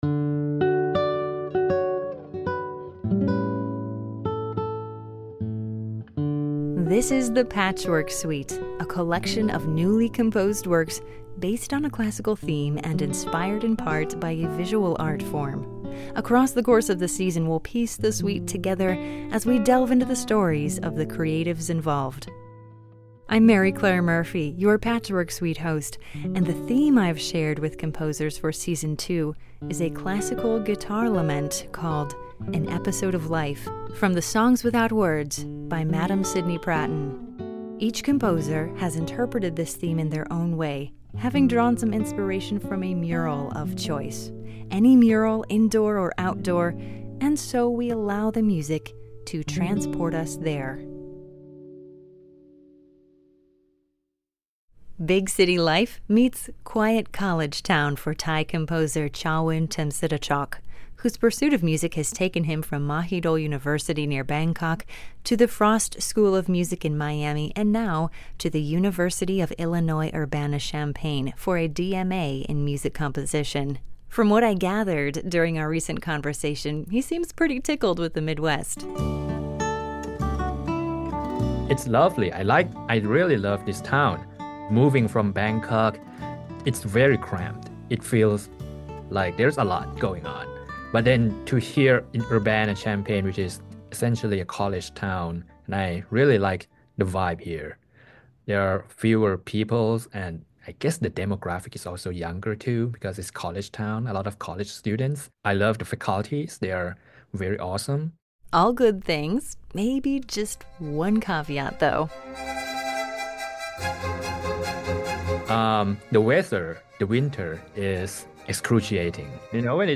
musical streetscape collage
lament emerge from a collection of found sounds